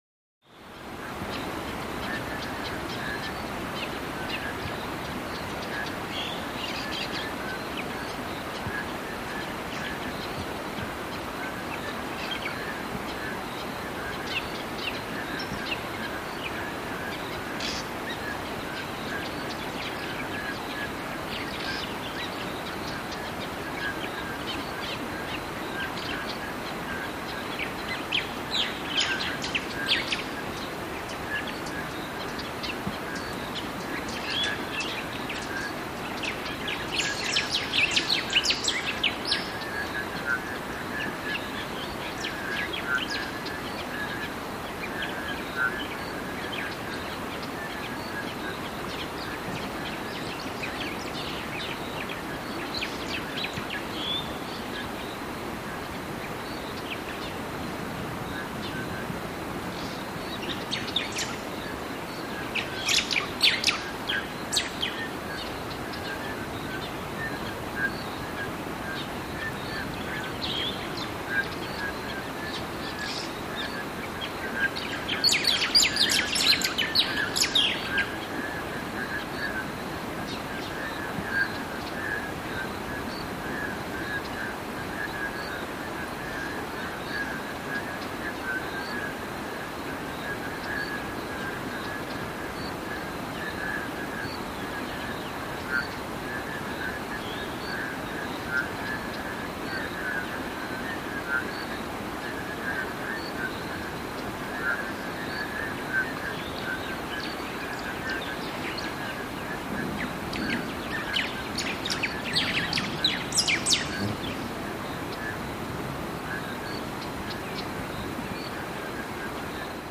Late Night Ambience Of Continuous Distant Frog Croaks With Close Perspective Bird Calls, Insects And Light Wind. One Close Perspective Insect Fly By At Tail.